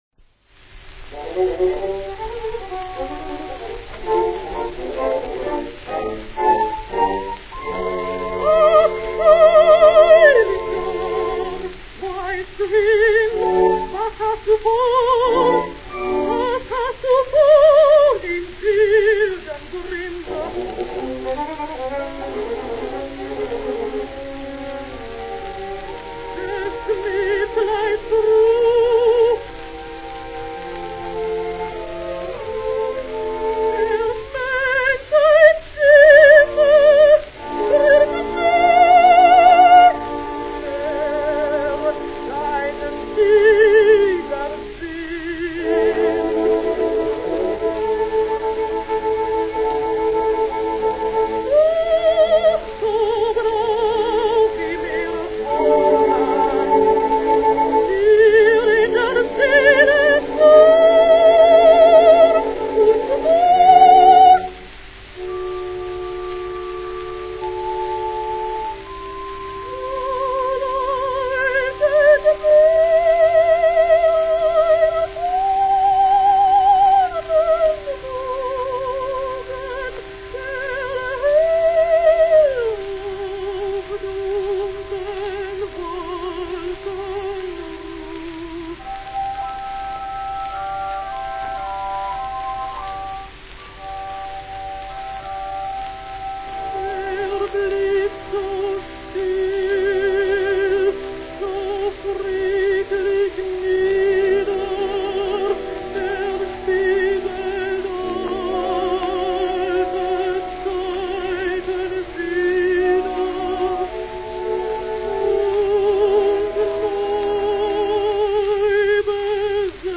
German soprano, 1888 - 1975